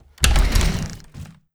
door sounds